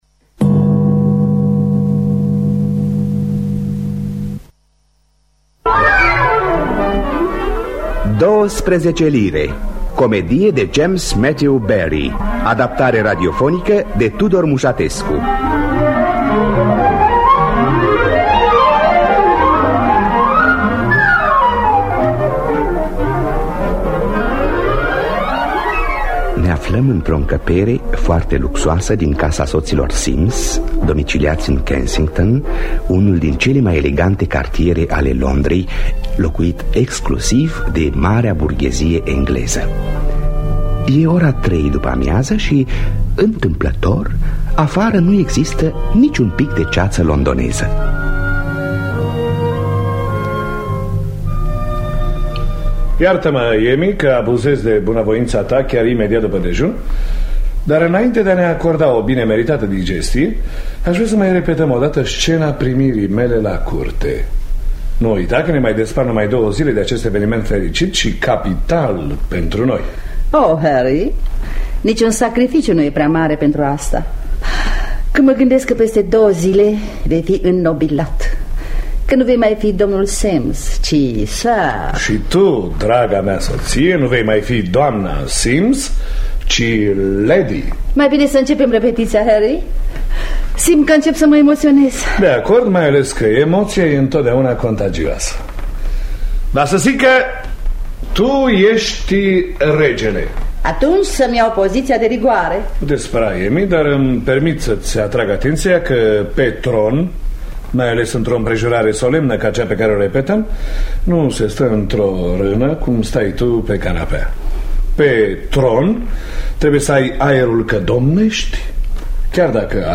Adaptarea radiofonică: Tudor Muşatescu.
În distribuţie: Toma Caragiu, Elvira Godeanu, Marcela Rusu.